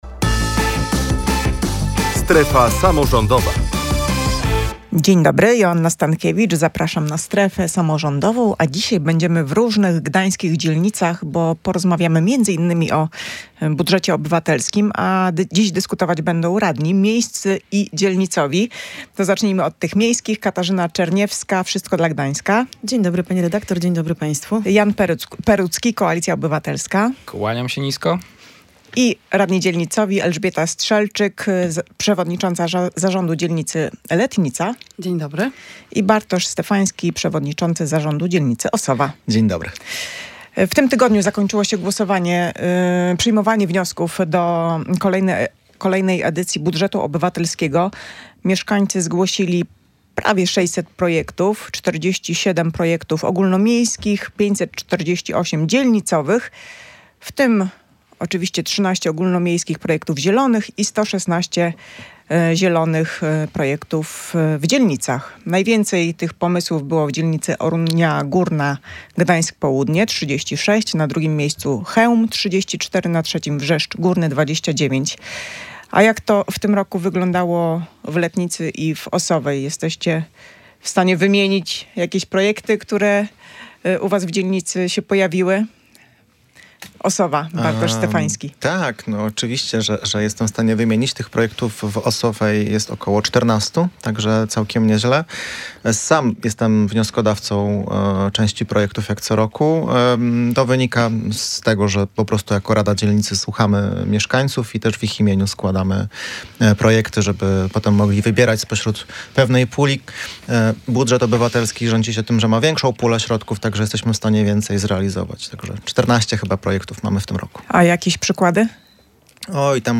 Obie strony dyskutowały na ten temat w „Strefie Samorządowej” Radia Gdańsk.